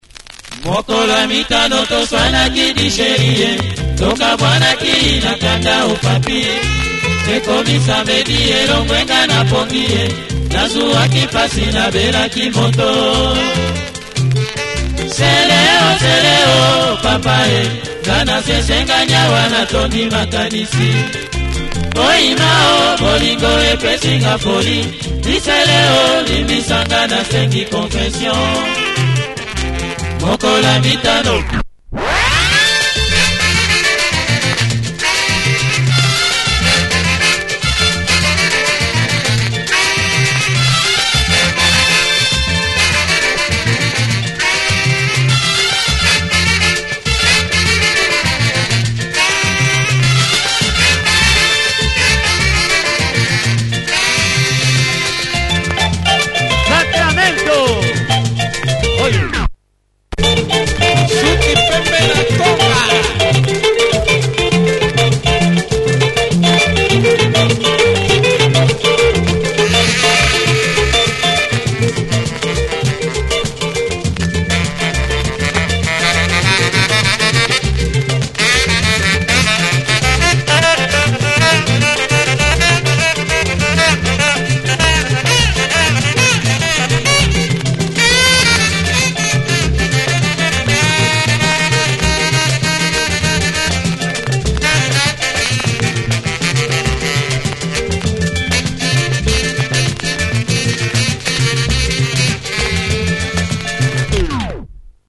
Orchestra
Great guitar driven lingala with horns.